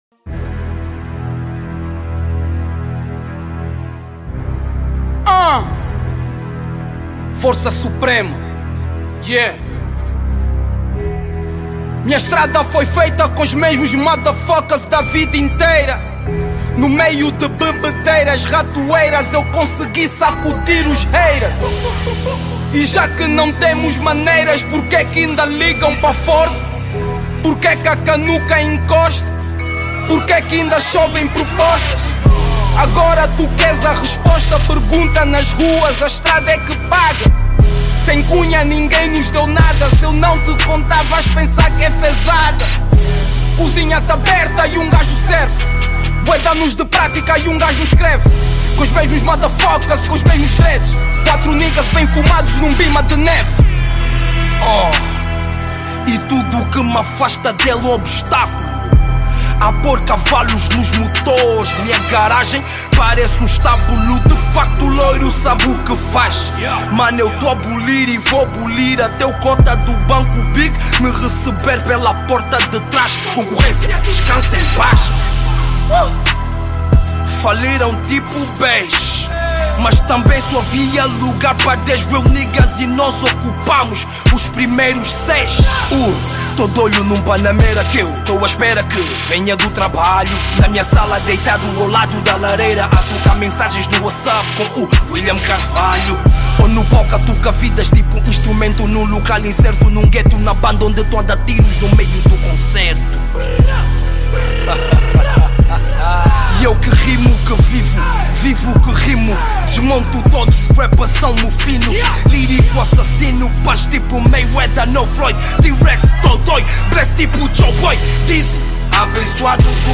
Rap HoT ★ ★ ★ ★ ★ Download Directo